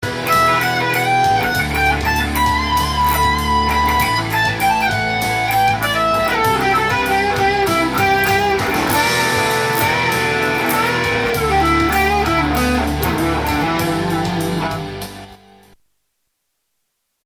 音の方は、ディストーションのエフェクター必須ですので
ヘビーな音楽をするために製造された専用機になっています。
７弦がBの音になっているようです。